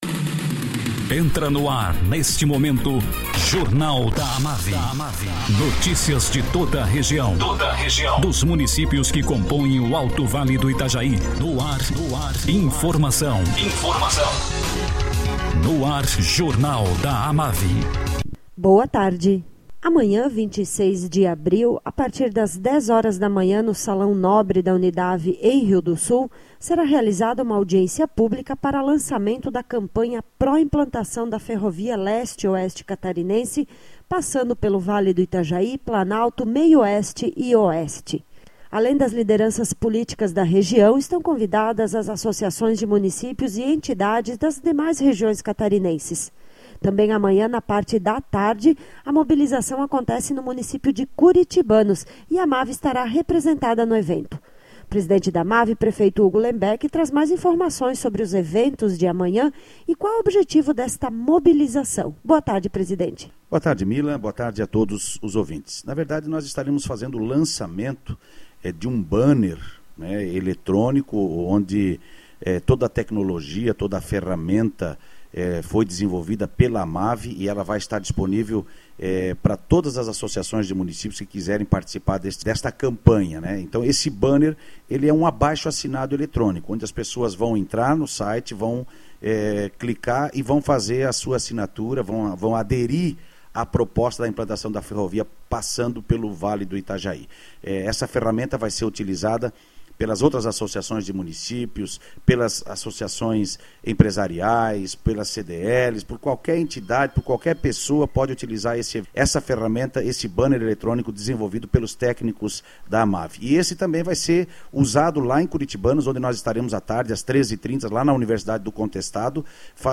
Presidente da AMAVI, prefeito Hugo Lembeck, fala sobre audiência pública, que será realziada amanhã, para lançamento da Campanha Pró-Implantação da Ferrovia Leste-Oeste Catarinense.